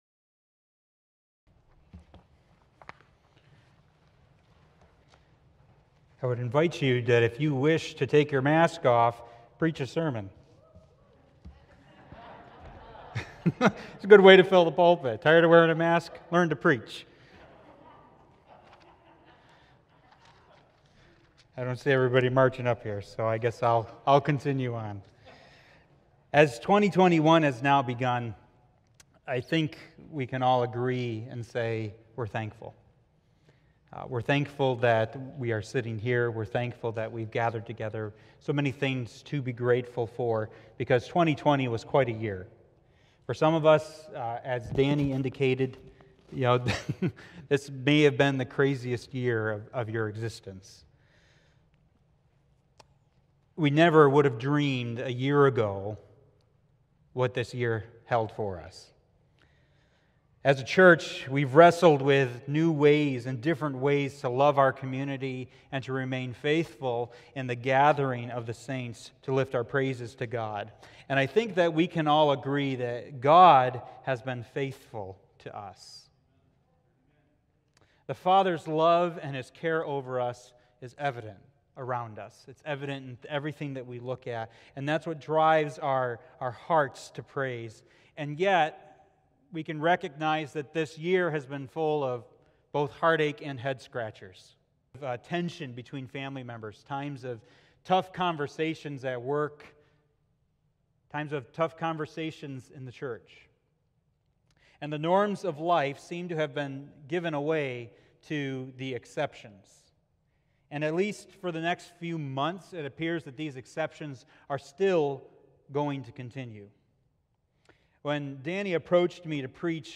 January-3-2021-Sunday-Service.mp3